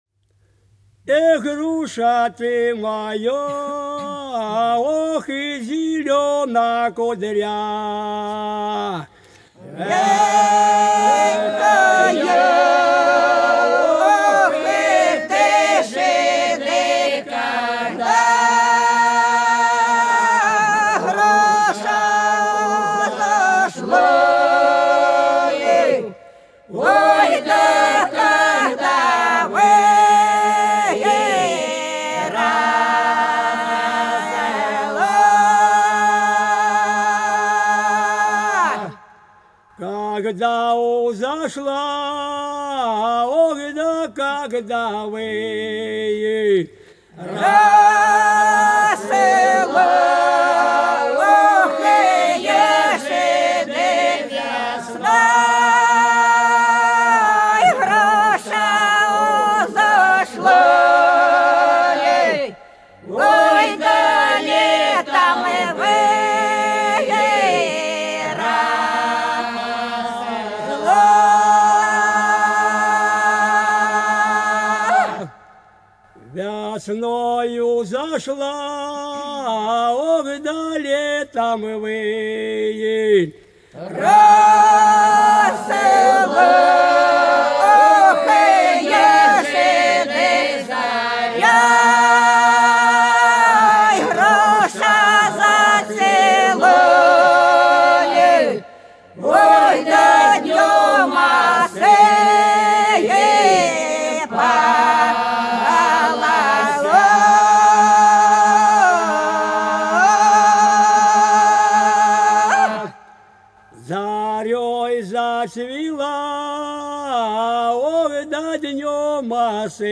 По-над садом, садом дорожка лежала Эх, груша ты моя зелёная кудрявая - протяжная (с.Фощеватово, Белгородская область)
28_Эх,_груша_ты_моя_зелёная_кудрявая_(протяжная).mp3